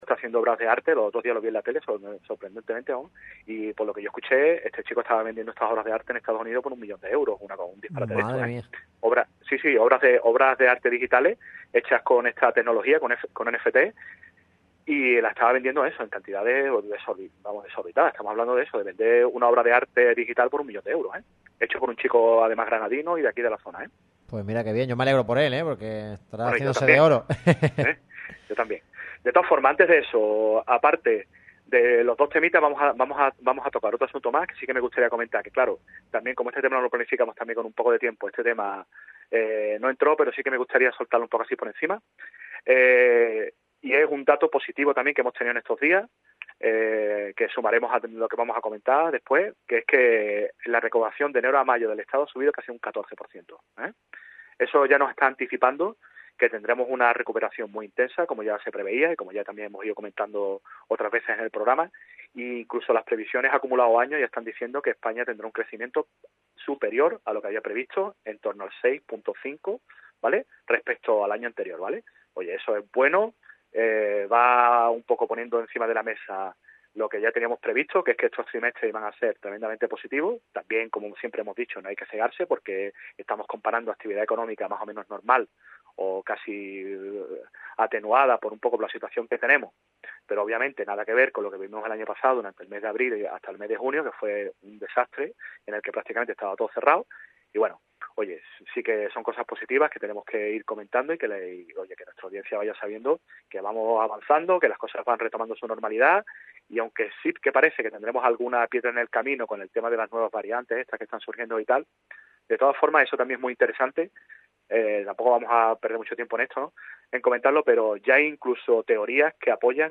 repasó la actualidad económica de la mano de K3 asesores en los micrófonos de Radio MARCA Málaga.